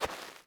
mining sounds
DIRT.1.wav